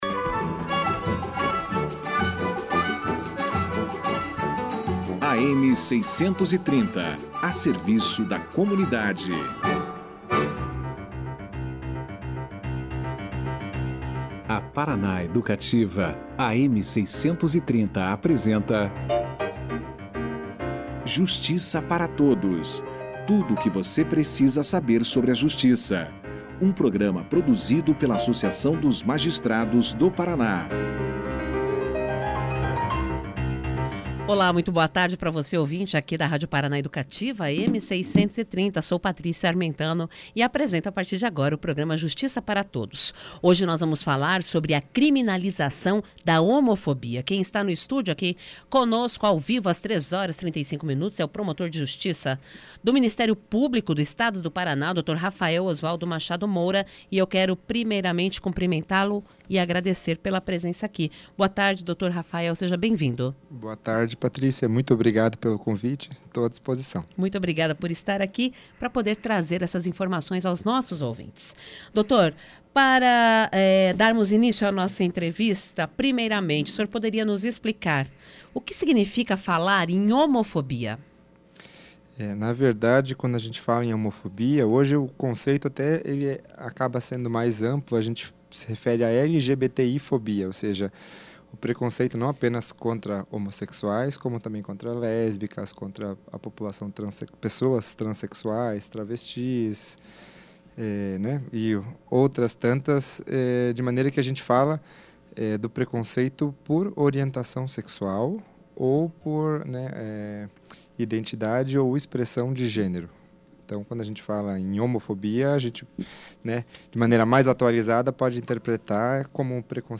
A pena prevista para este tipo de crime é de um a três anos, podendo chegar a cinco anos em casos mais graves. Confira aqui a entrevista na íntegra.